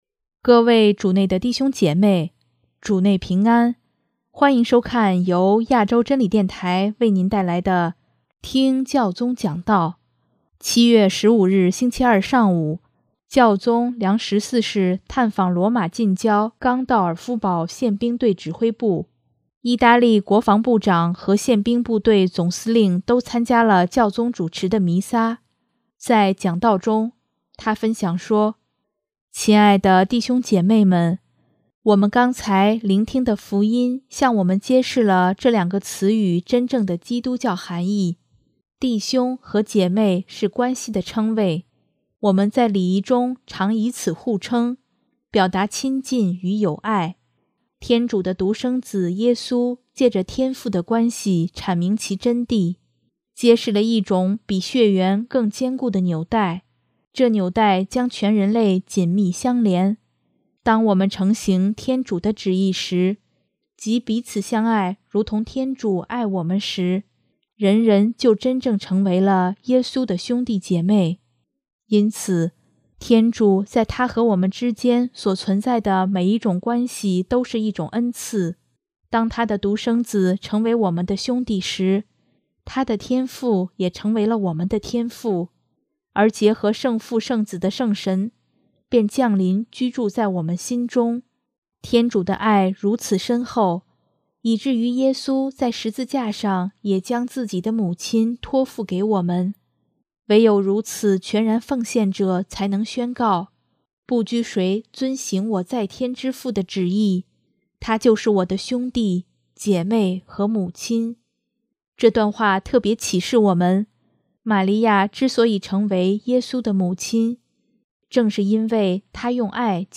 7月15日星期二上午，教宗良十四世探访罗马近郊冈道尔夫堡宪兵队指挥部，意大利国防部长和宪兵部队总司令都参加了教宗主持的弥撒。